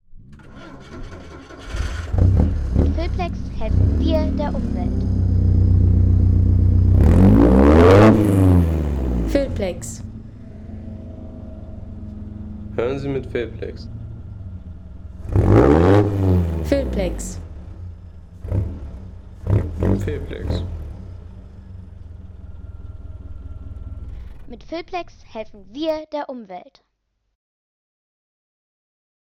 Bentley 4½ Litre „Blower“ - 1929
Der Oldtimer-Racer der 20er Jahre – Ein akustisches Highlight.